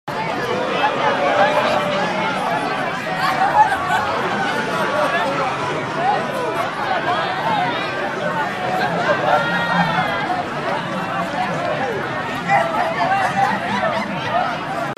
دانلود صدای صحبت و خنده مردم از ساعد نیوز با لینک مستقیم و کیفیت بالا
جلوه های صوتی
برچسب: دانلود آهنگ های افکت صوتی طبیعت و محیط دانلود آلبوم صدای محیط از افکت صوتی طبیعت و محیط